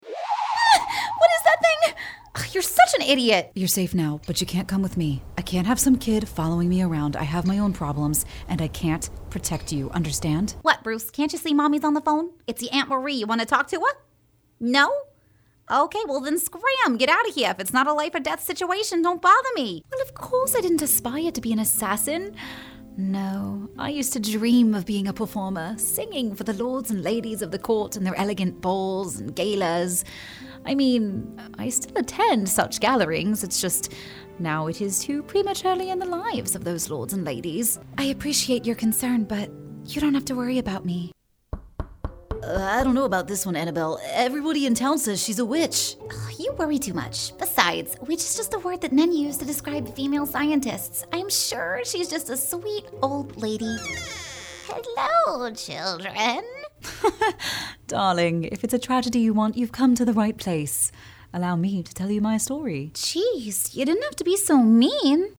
TV & Voice Demos
CHARACTER READINGS